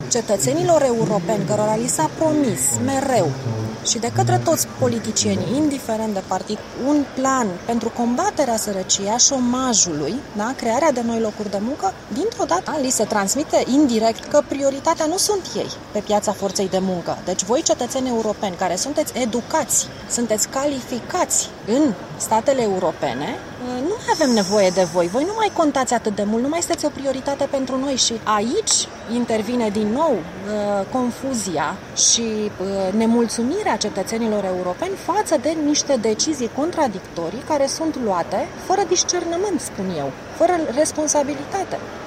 Eurodeputatul Ramona Mănescu, declaraţii